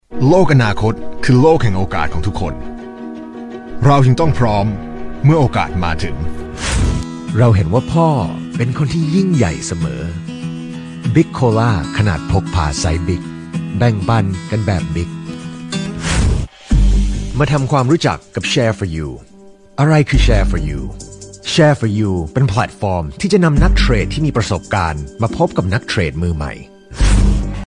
男泰语01
男泰语01 泰语男声 广告MG动画旁白 沉稳|娓娓道来|积极向上|时尚活力|素人
男泰语01 泰语男声 干音 沉稳|娓娓道来|积极向上|时尚活力|素人